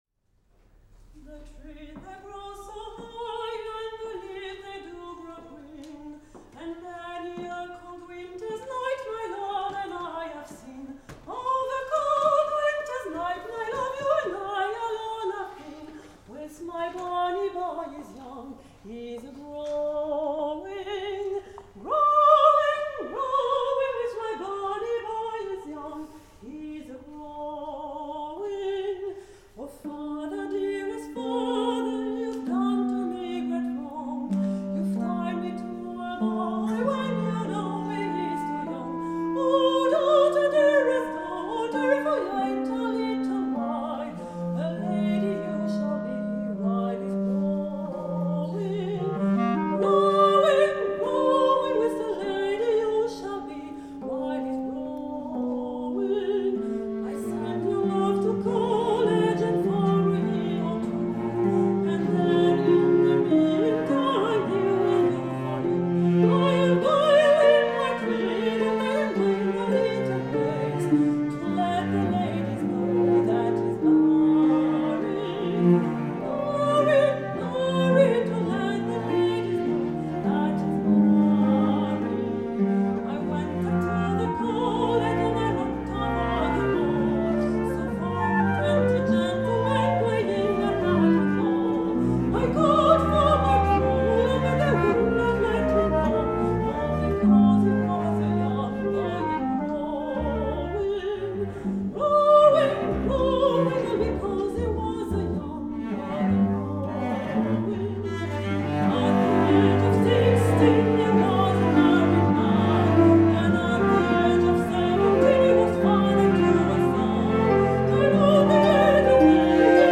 Chanson populaire
transcrite pour soprano et sept instruments